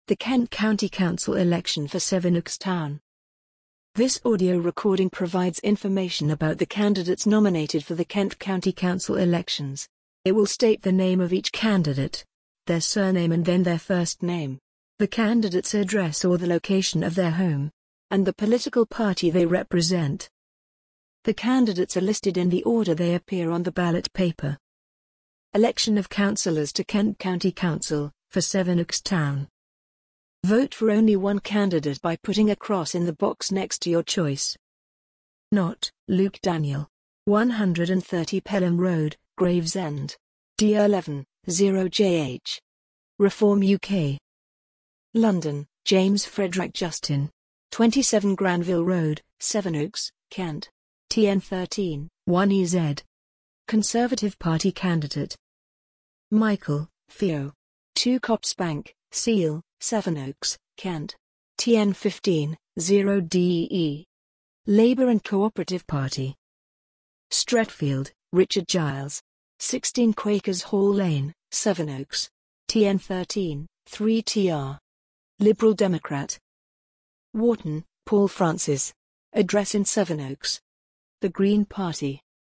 Sevenoaks District Council download - KCC election audio ballot papers | Your council | Elections and voting